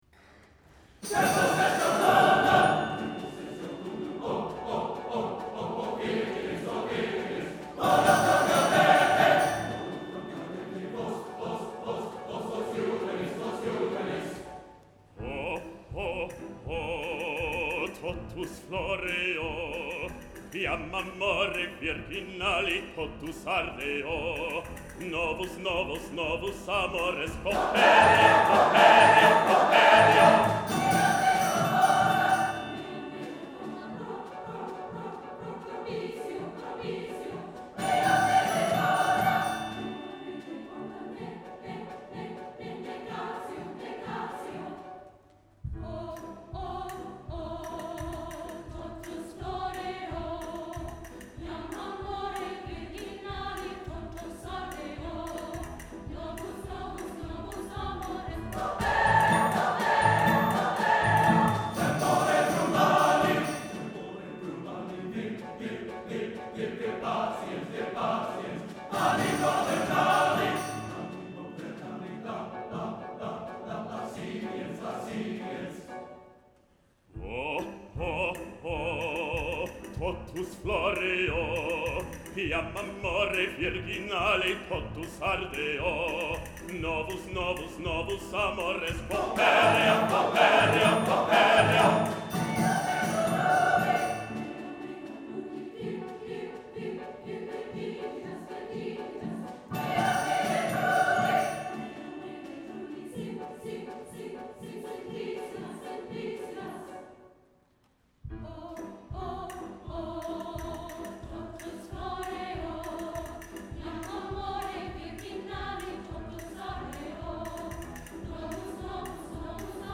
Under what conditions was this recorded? Saturday evening performance.